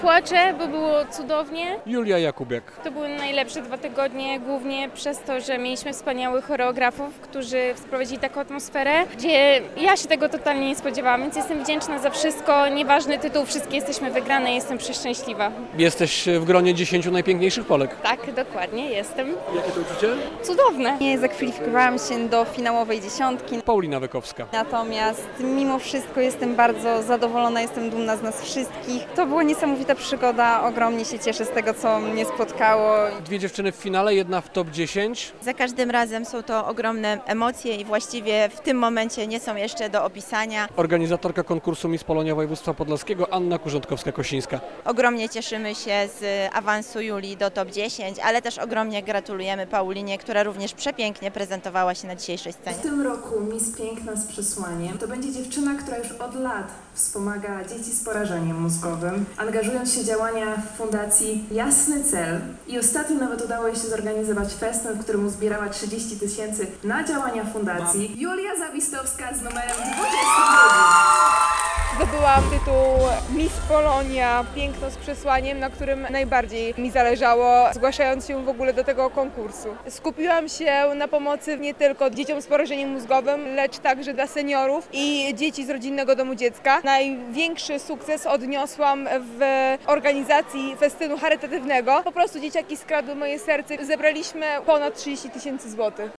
Miss Polonia 2024 - relacja